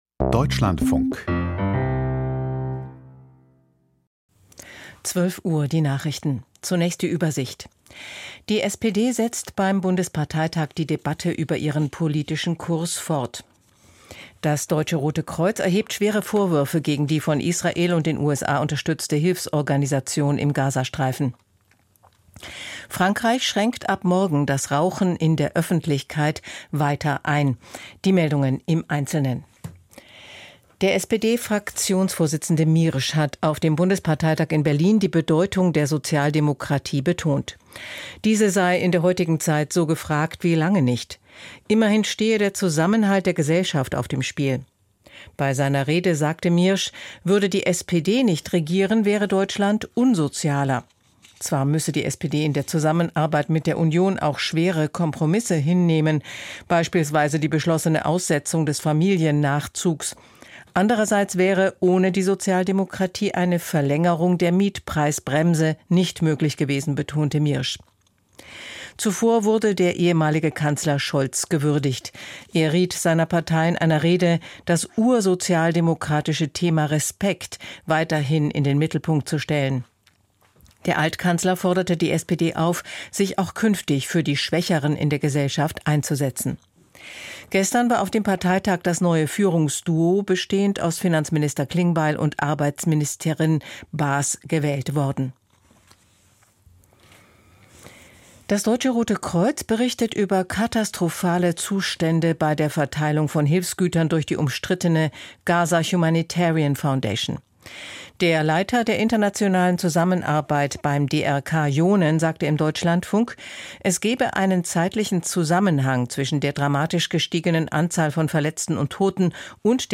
Die wichtigsten Nachrichten aus Deutschland und der Welt.